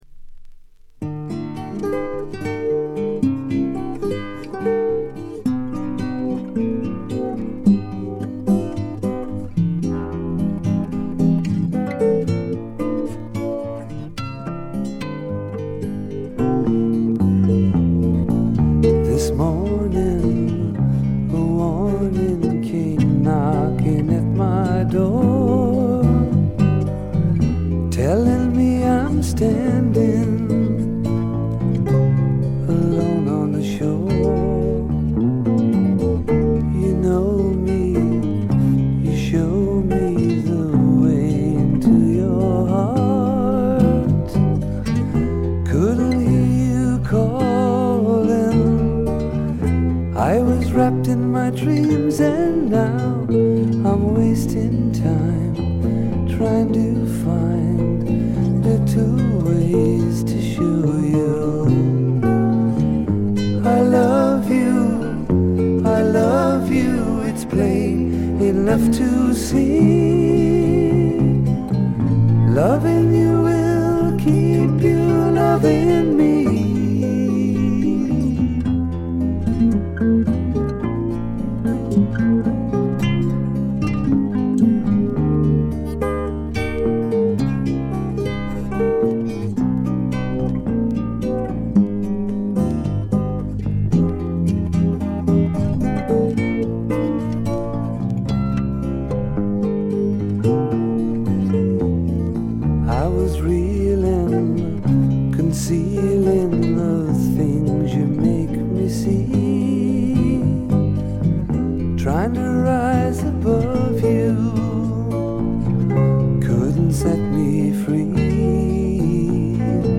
これ以外はわずかなノイズ感のみで良好に鑑賞できると思います。
中でも静謐でありながら暖かな魅力があふれる自作のフォーク路線がとりわけ最高ですね。
試聴曲は現品からの取り込み音源です。